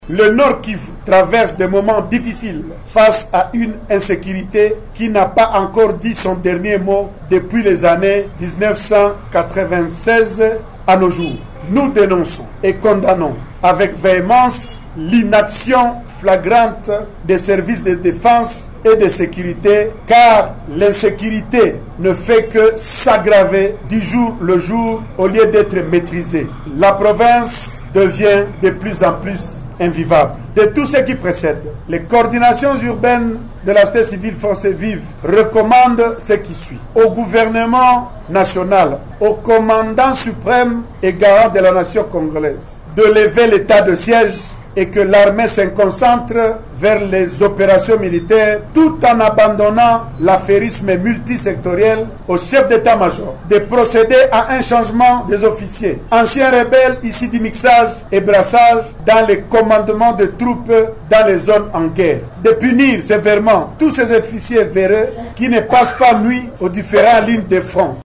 Vous pouvez écouter l’un de participants à cette réunion